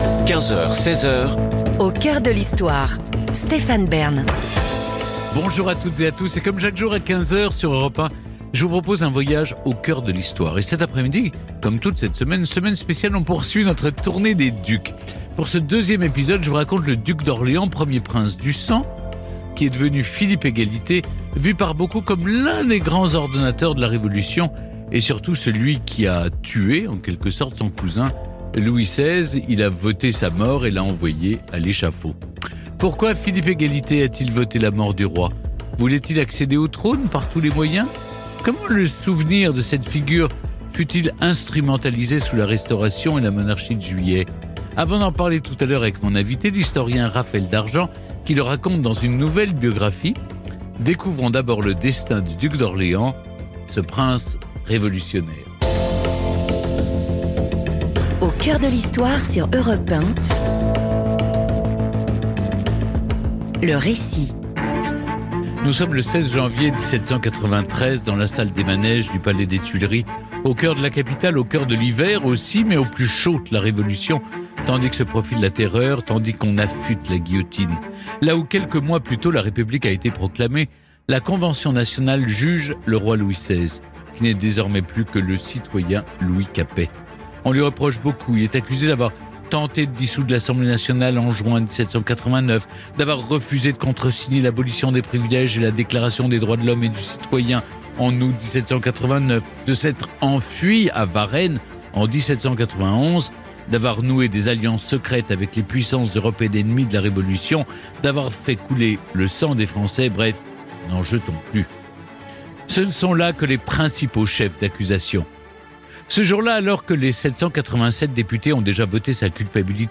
Cette entrevue témoigne de l’intérêt croissant pour ce travail original et méticuleux.